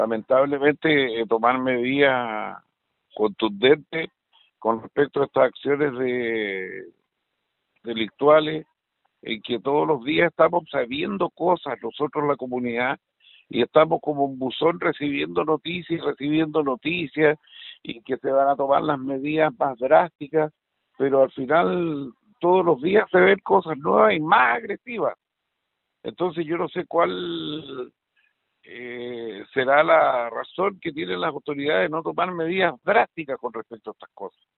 El lugareño agregó que esta situación y el homicidio de una comerciante ocurrido hace en el sector hace un año en Liucura, ha alarmado a la comunidad y pide que la autoridades tomen carta en el asunto para que la violencia no siga aumentando.